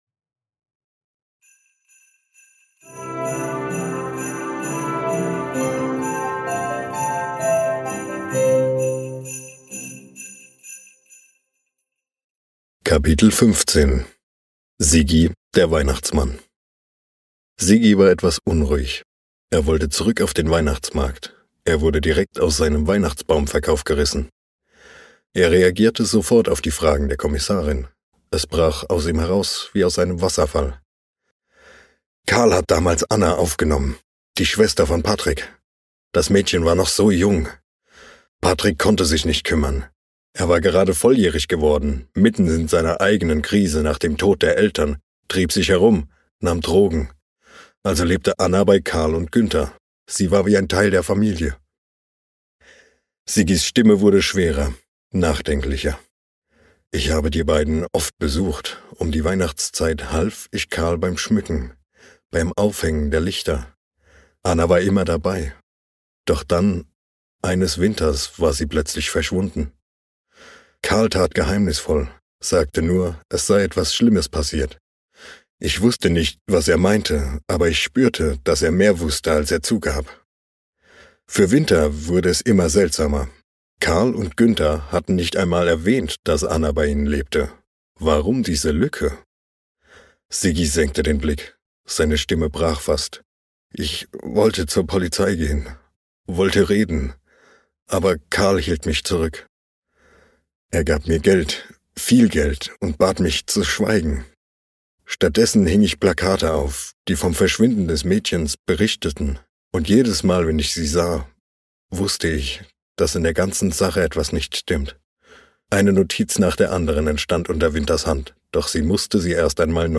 Lass dich von acht verzaubernden Stimmen in die